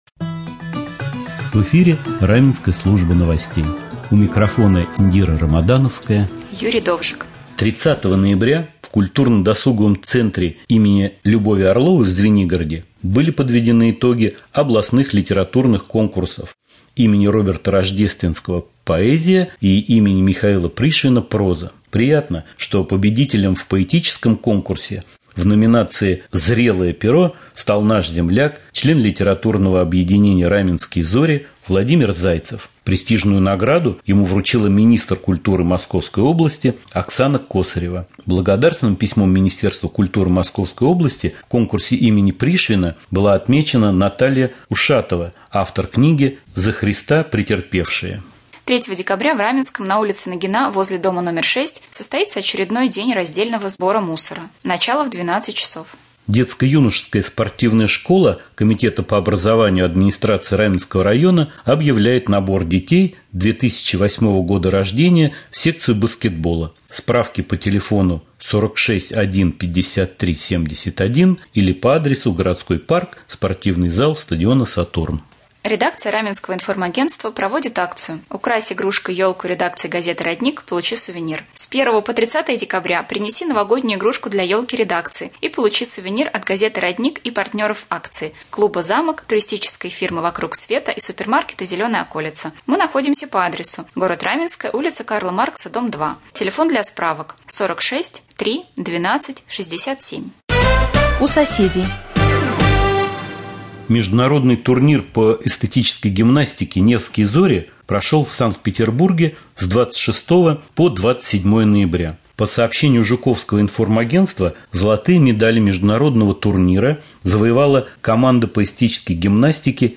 2. В прямом эфире